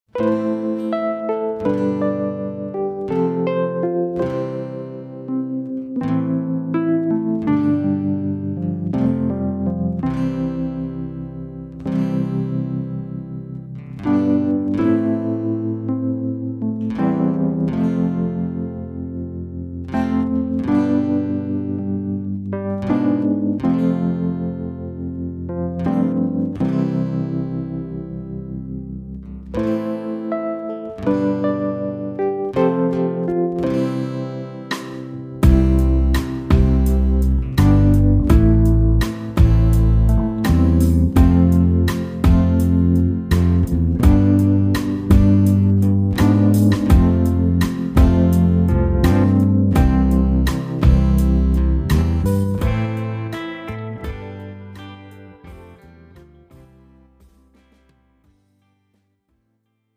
팝송